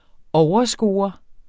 Udtale [ ˈɒwʌˌsgoːʌ ]